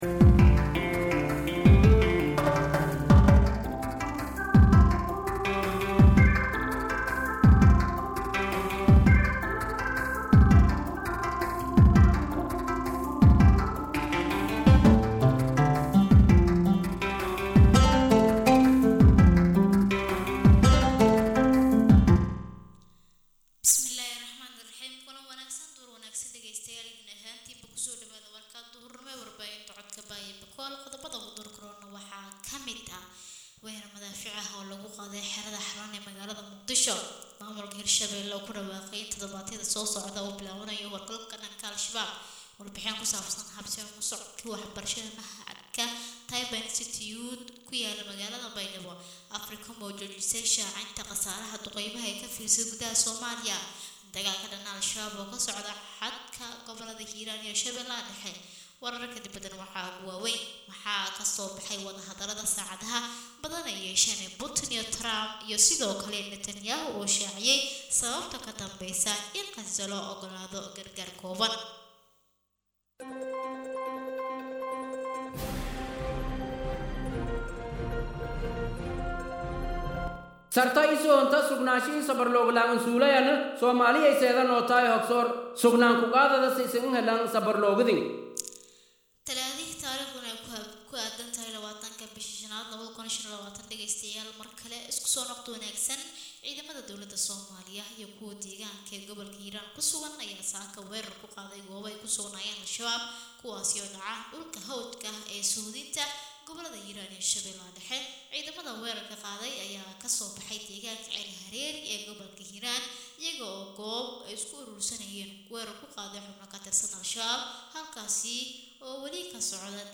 {DHAGEYSO} Warka Duhurnimo ee Warbaahinta Radio Codka Baay Iyo Bakool {21.5.2025}